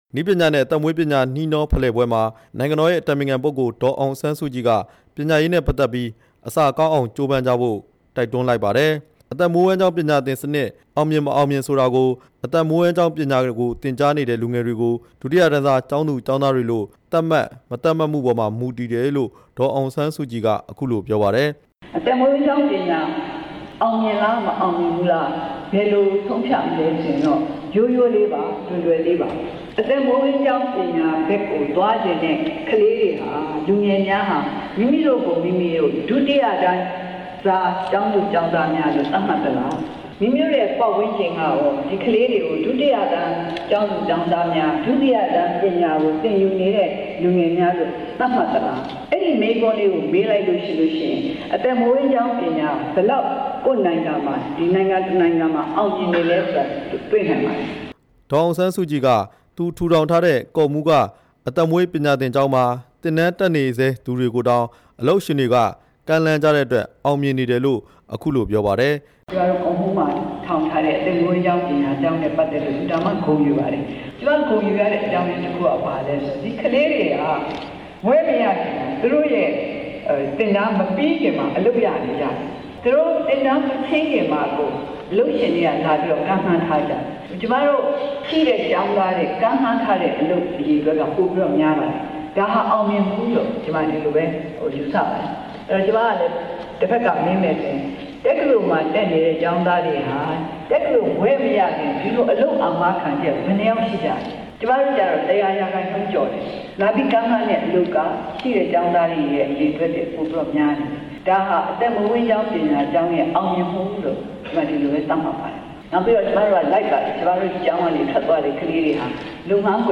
ဒီကနေ့ နေပြည်တော်က မြန်မာအပြည်ပြည်ဆိုင်ရာ ကွန်ဗန်းရှင်းစင်တာ ၂ မှာ နှစ်ရက်တာကျင်းပမယ့် နည်းပညာနဲ့ သက်မွေးပညာနှီးနှောဖလှယ်ပွဲ ဖွင့်ပွဲအခမ်းအနားမှာ ပြောကြားခဲ့တာဖြစ်ပါတယ်။
နည်းပညာနဲ့ သက်မွေးပညာနှီးနှောဖလှယ်ပွဲကို နေပြည်တော်က မြန်မာ အပြည်ပြည်ဆိုင်ရာ ကွန်ဗန်းရှင်းစင်တာ ၂ မှာ ဒီကနေ့ စတင်ကျင်းပပါတယ်။ အဲ့ဒီပွဲမှာ နိုင်ငံတော် အတိုင်ပင်ခံပုဂ္ဂ္ဂ္ဂိုလ် ဒေါ်အောင်ဆန်းစုကြည် က သက်မွေးဝမ်းကျောင်းပညာ အရေးကြီးတဲ့အကြောင်း တက်ရောက် စကားပြောကြားခဲ့ပါတယ်။